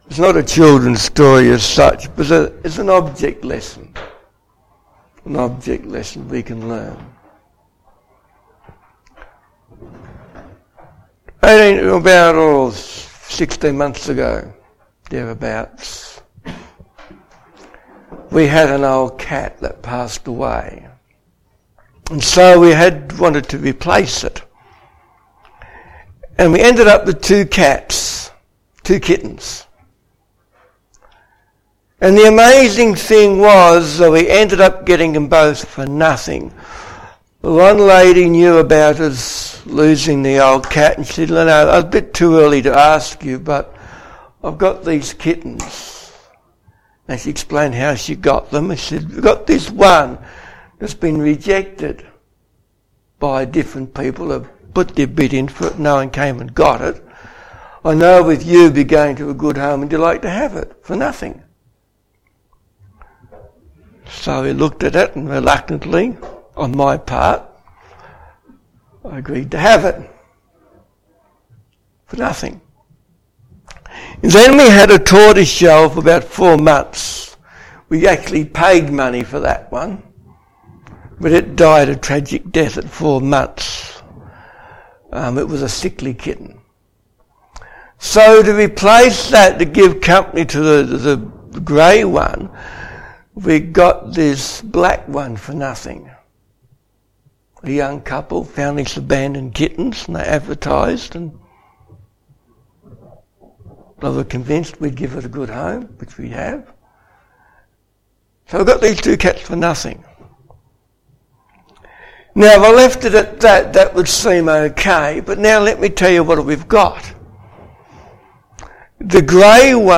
Children's Stories
SABBATH SERMONS